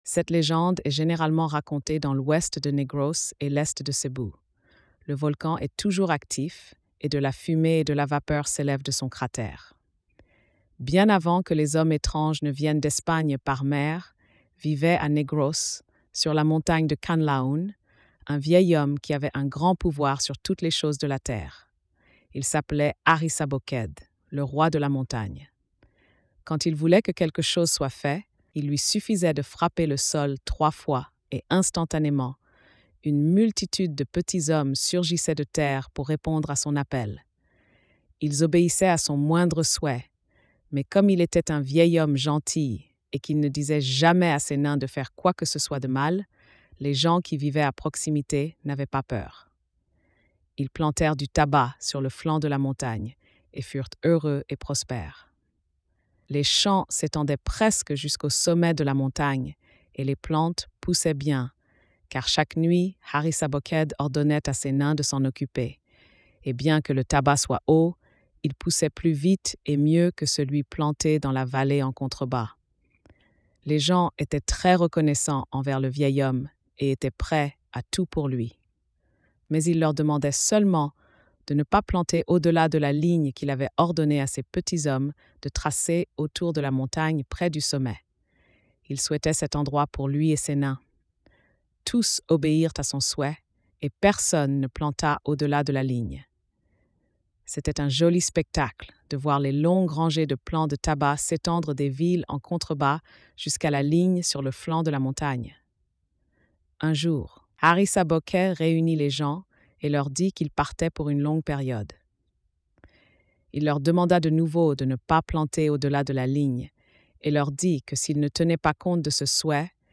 Conte philippin
🎧 Lecture audio générée par IA